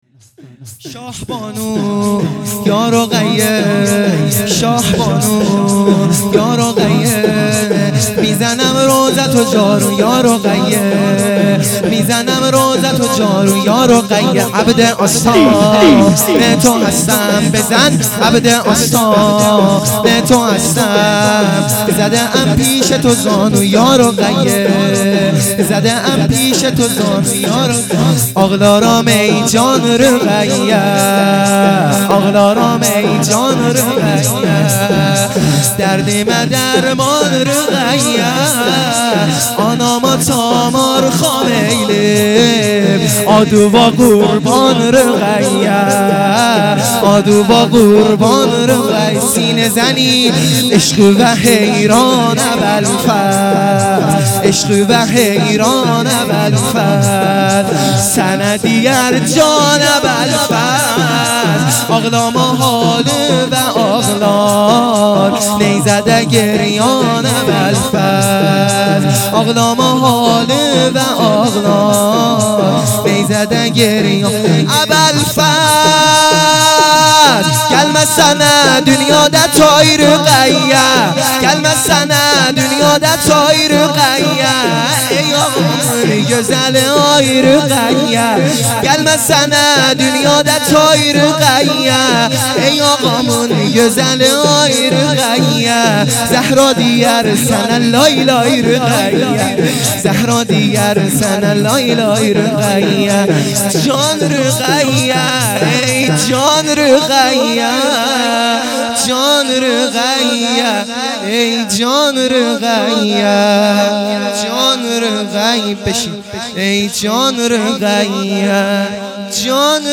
شور ا شاه بانو یا رقیه